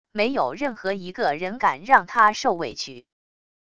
没有任何一个人敢让他受委屈wav音频生成系统WAV Audio Player